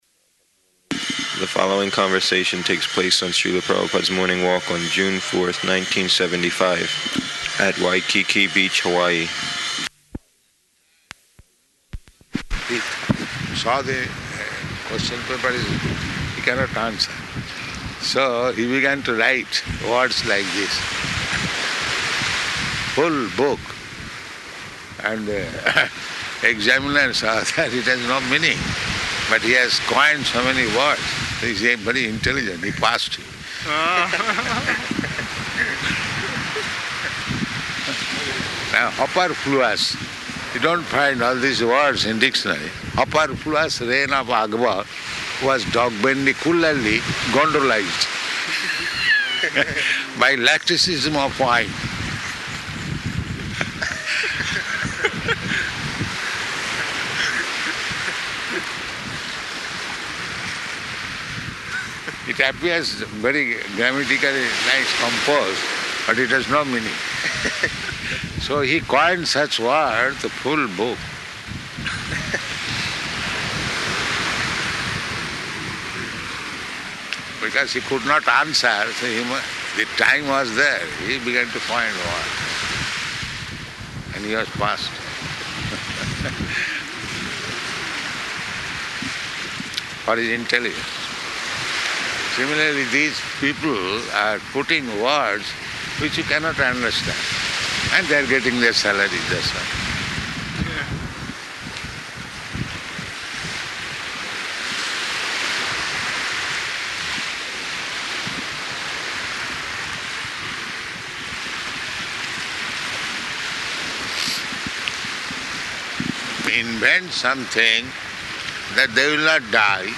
Morning Walk at Waikiki Beach
Type: Walk
Location: Honolulu